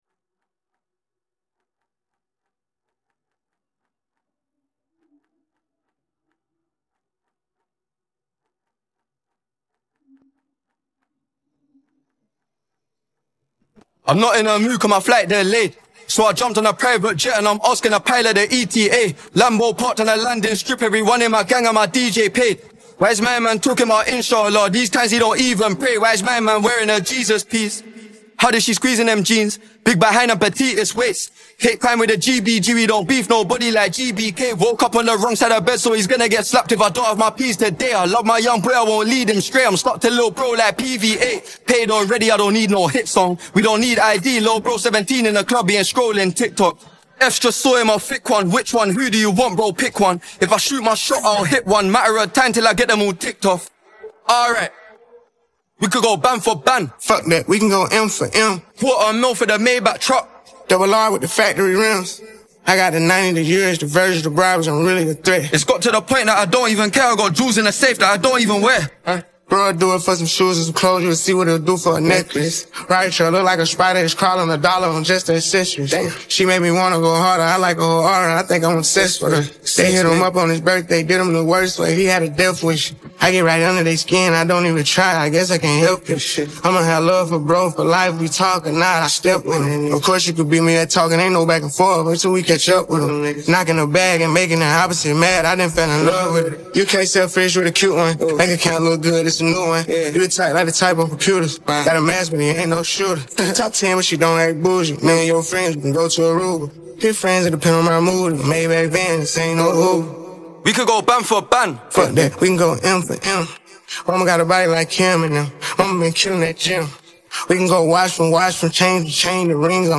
Vocal Part